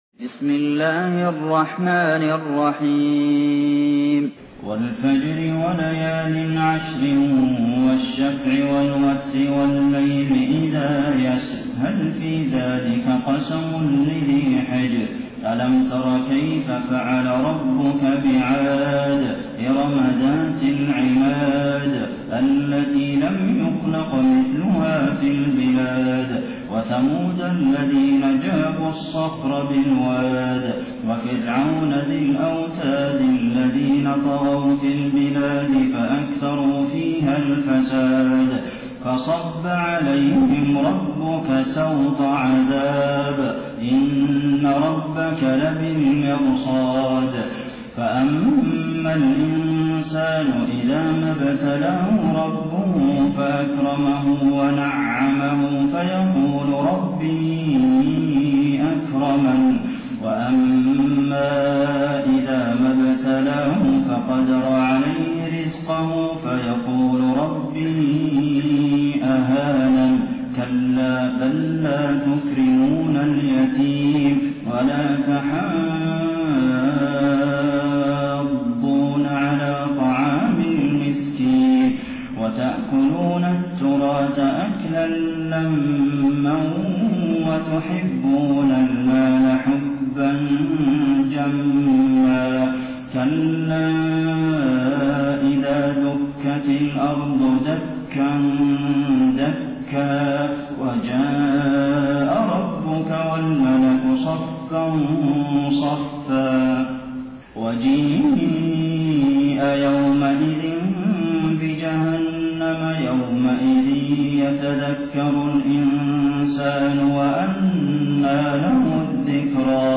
taraweeh-1433-madina